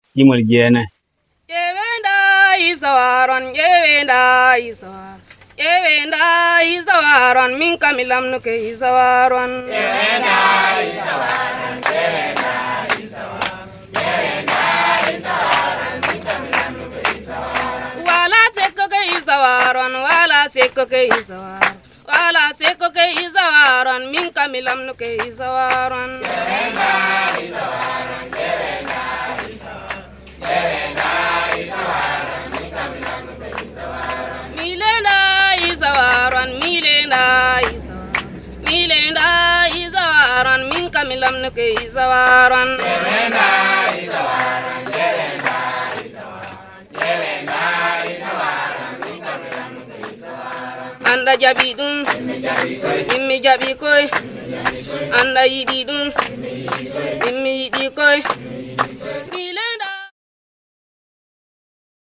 Cantique en fulfulde | Fulfulde Media